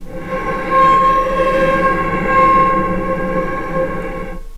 vc_sp-C#5-pp.AIF